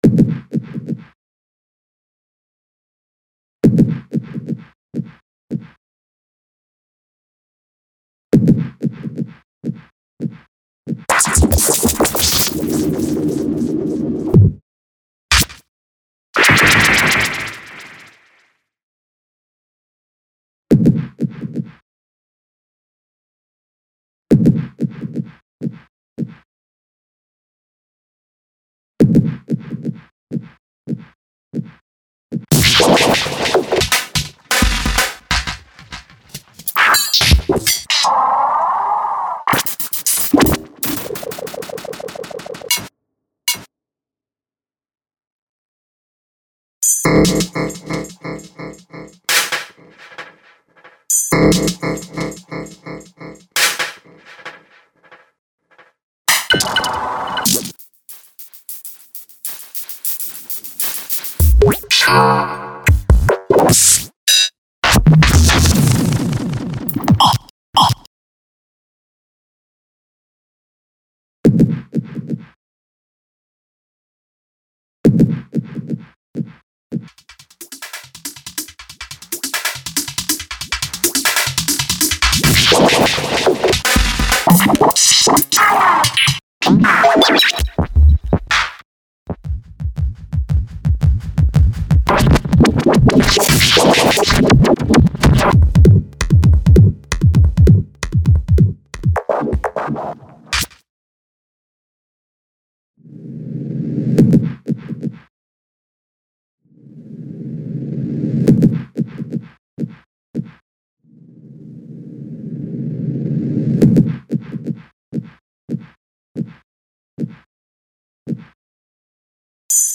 Structured-random miniatures.